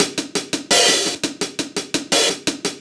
85bpm-funkriff-hats.wav